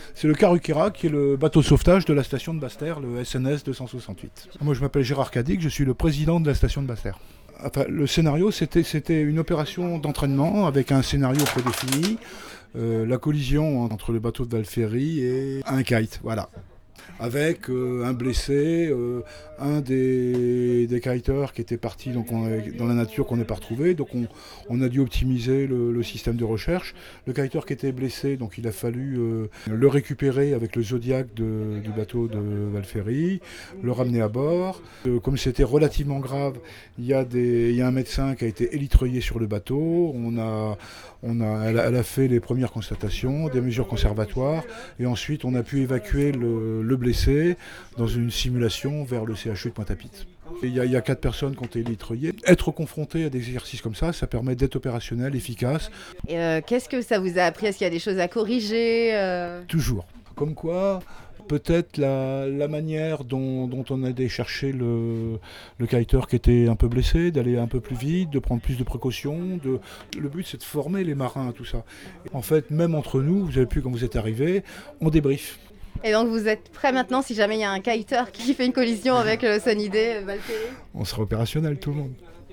interviewé sur la vedette d’intervention Karukera mardi après l’opération